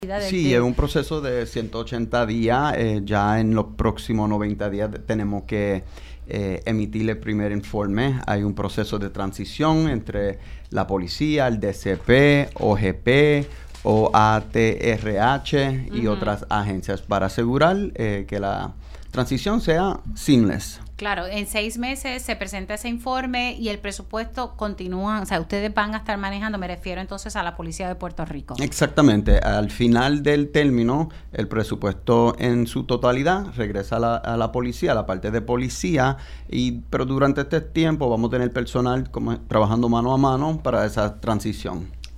Por su parte, el superintendente González celebró en Pega’os en la Mañana el nuevo capítulo que comenzará el cuerpo policíaco.